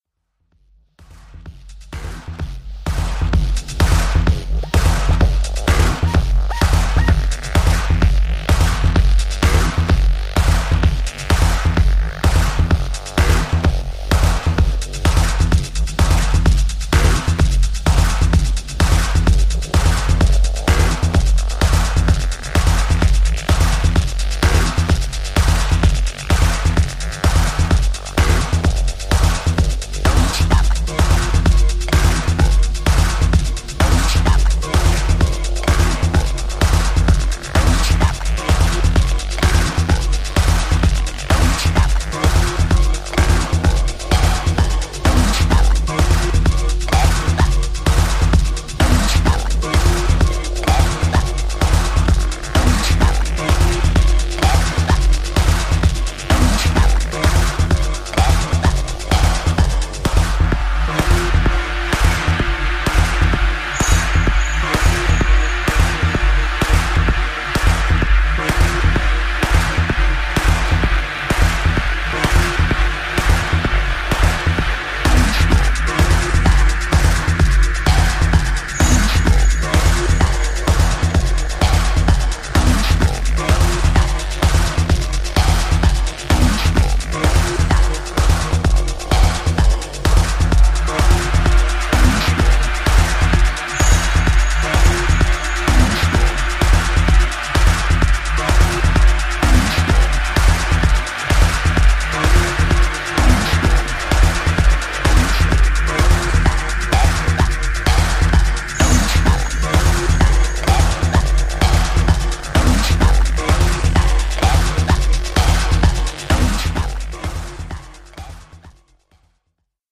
Styl: Techno